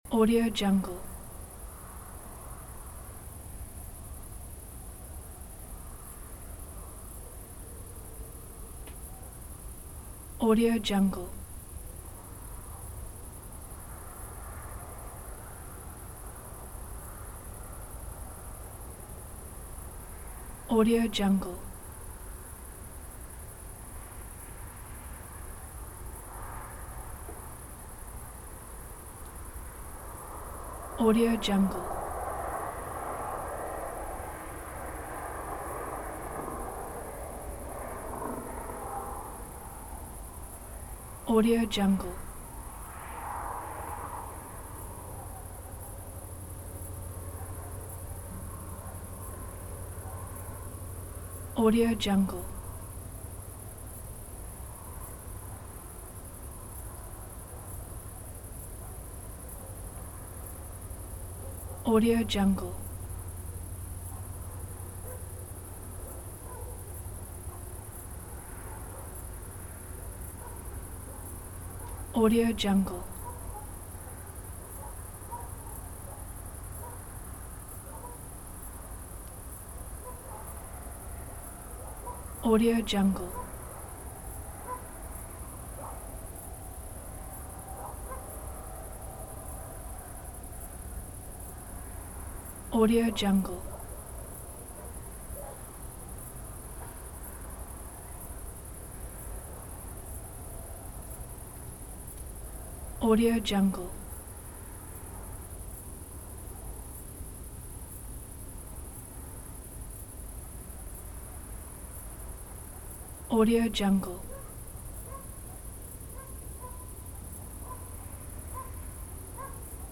دانلود افکت صوتی آمبیانس روستا در فاصله دور در فصل برداشت محصول